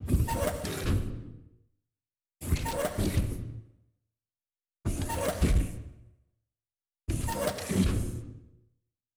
SFX_RoboSteps_Squeaky_06.wav